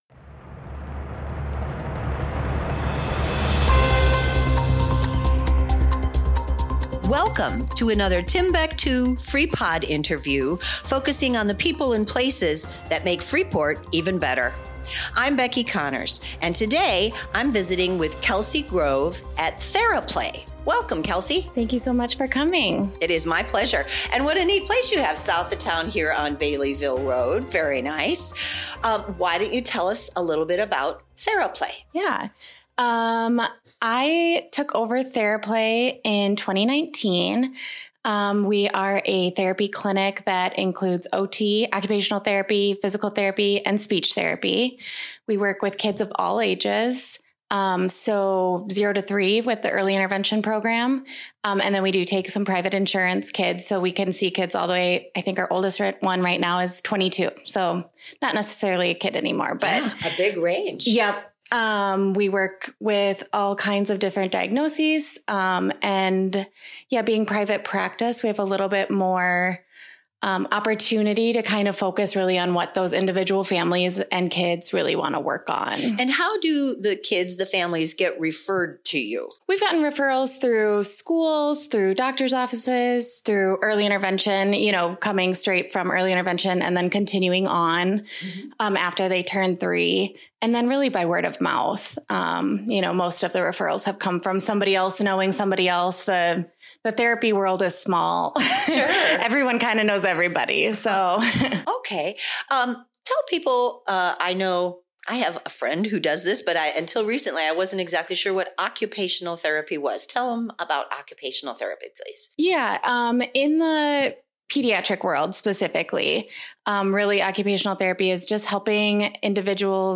Freepod Interview